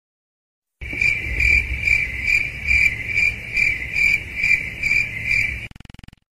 Awkward (Cricket Silence) Meme sound effects free download